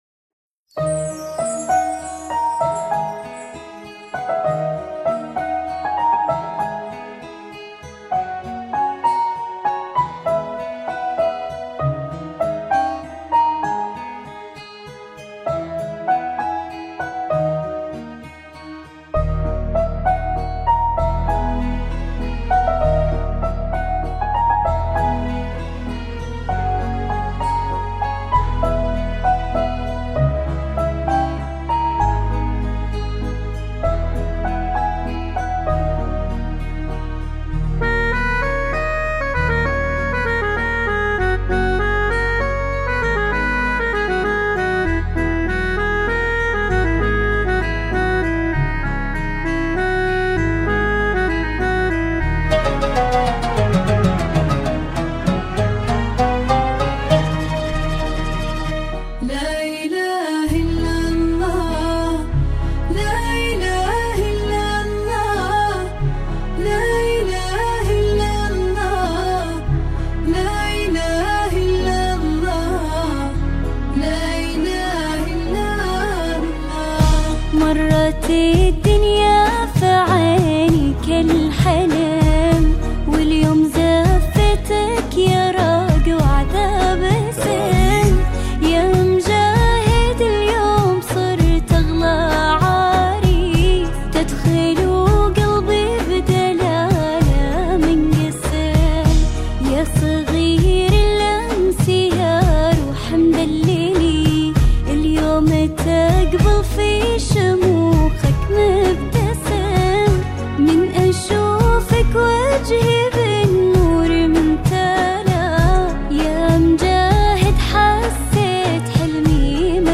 زفات 2025